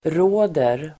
Uttal: [r'å:der el. r'å:r]